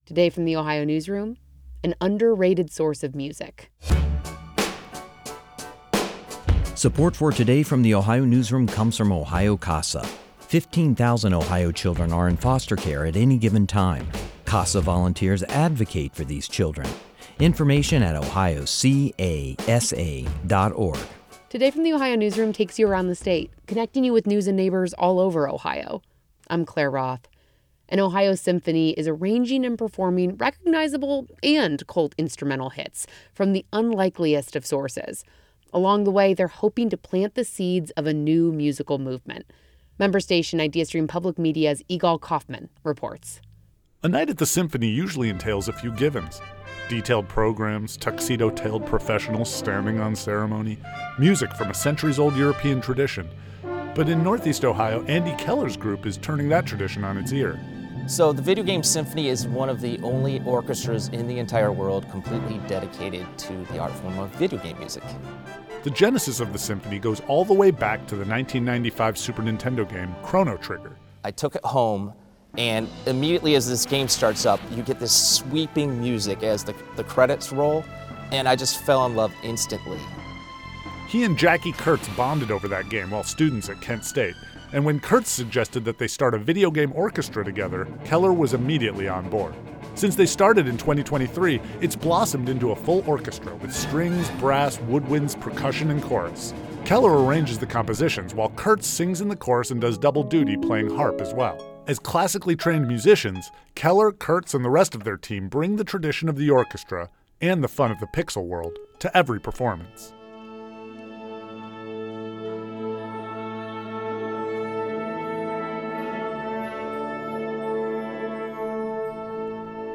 Just before the start of a recent concert at the Maltz Performing Arts Center in Cleveland, members of a symphony orchestra assembled on stage to tune their instruments.